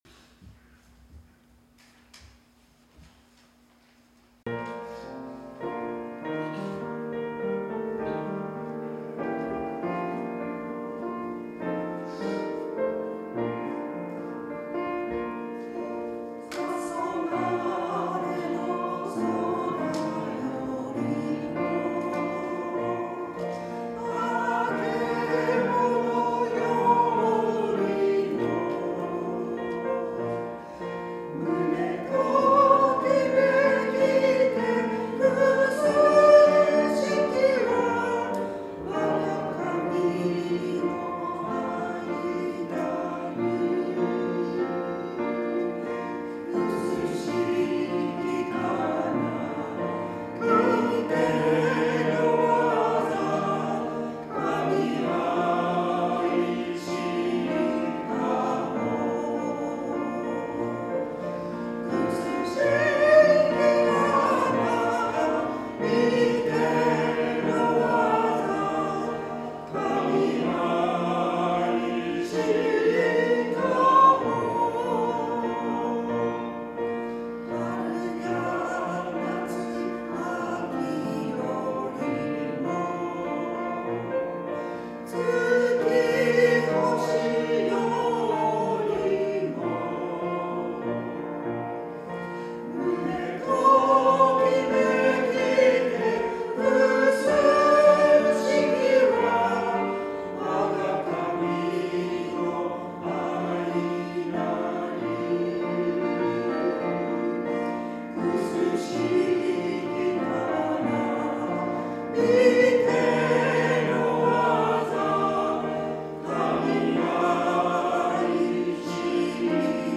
2024年11月3日聖歌隊賛美『たそがれの空よりも』音声ファイルです。